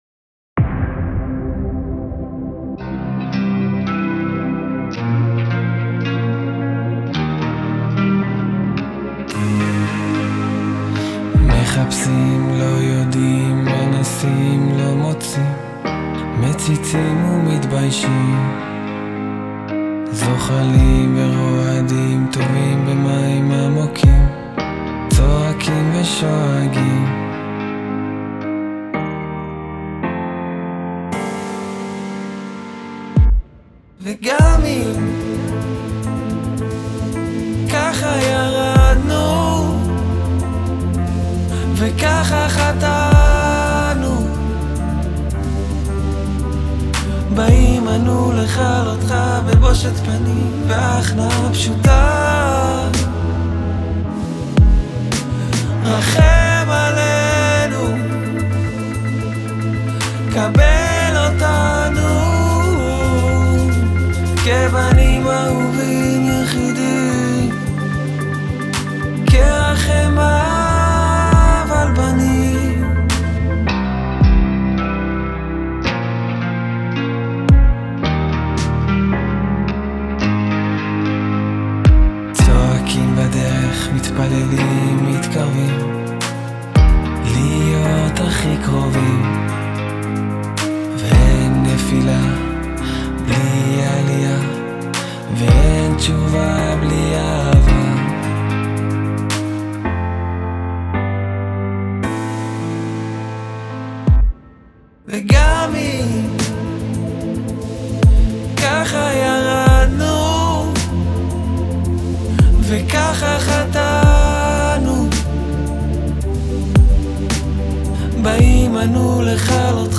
אשמח לתגובות/ הערות וכו’ [זה אחרי המיקס] תודה מראש בנים אהובים.mp3
אהבתי נקודה למחשבה היה חסר לי קצת התפתחות בהפקה — בבית ובפזמון השני ציפיתי לאיזה התפרצות וזה מאוד מתאים גם לסגנון של השיר – לדעתי זה היה משדרג ביותר